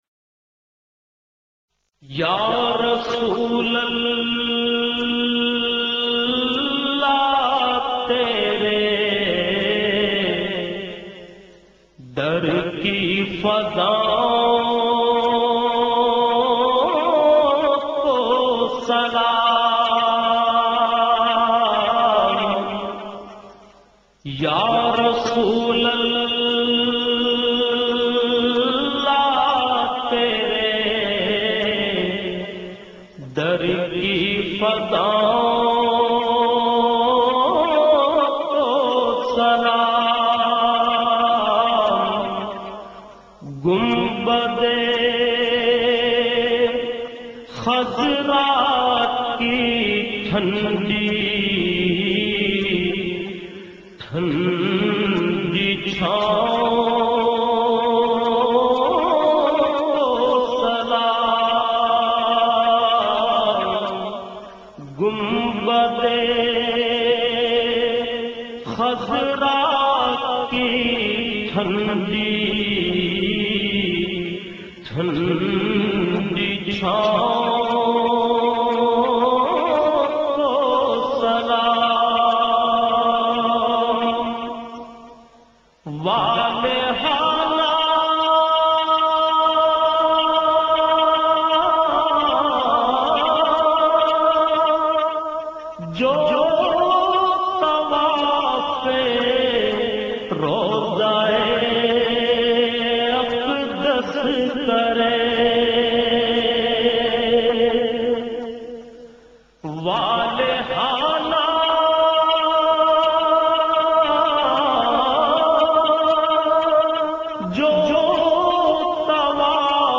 Hamd and Naat Khawan